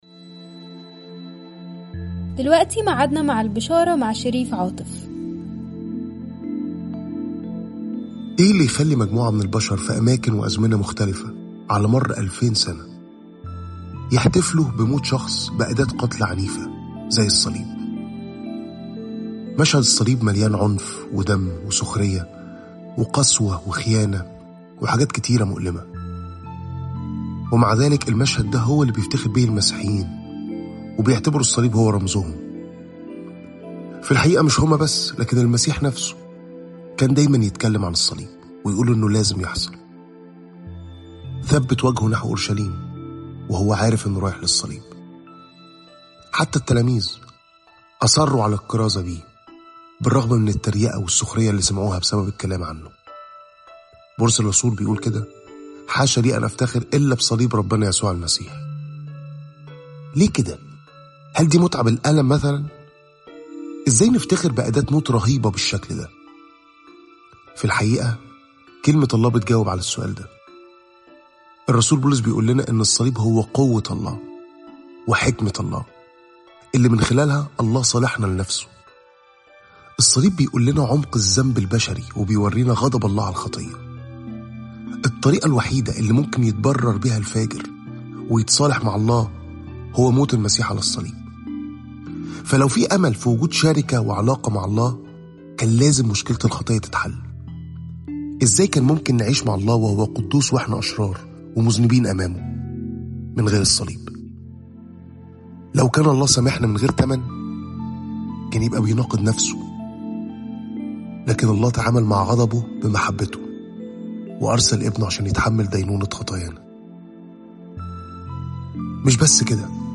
تأملات مُركّزة باللهجة المصرية عن عُمق وروعة صليب المسيح وقيامته